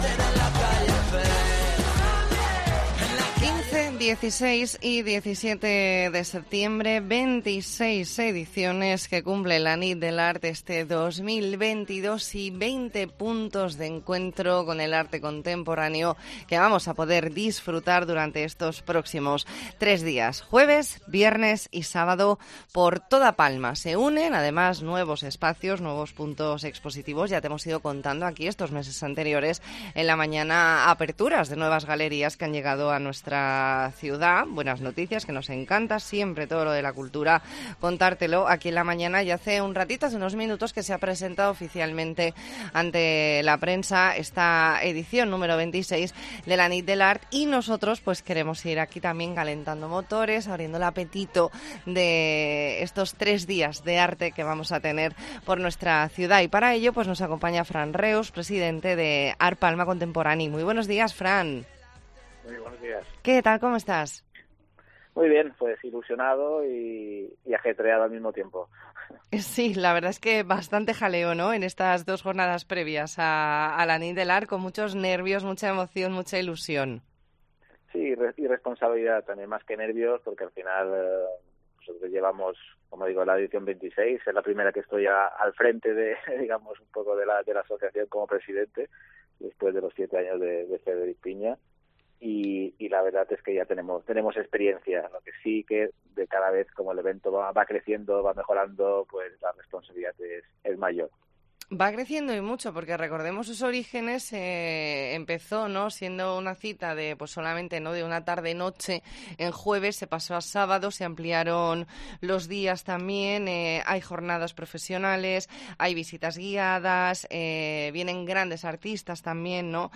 E ntrevista en La Mañana en COPE Más Mallorca, martes 13 de septiembre de 2022.